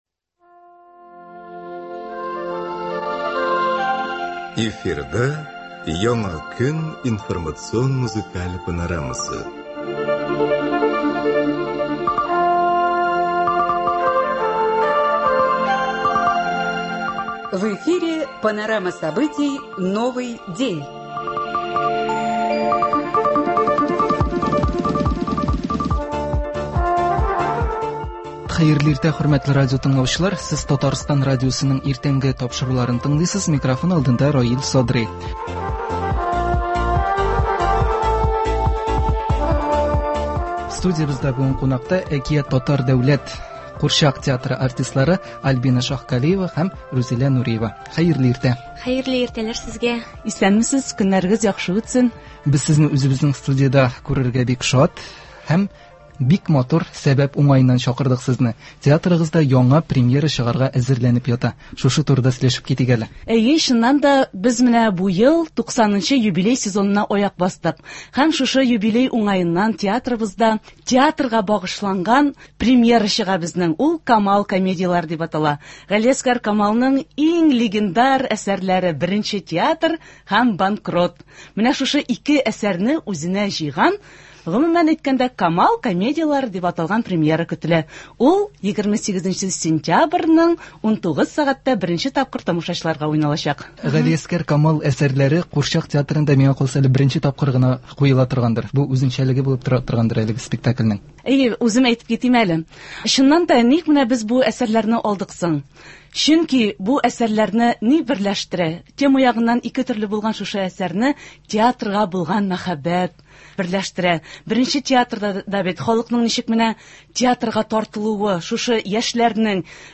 Туры эфир (25.09.23)